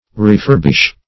Refurbish \Re*fur"bish\ (r?*f?r"b?sh), v. t.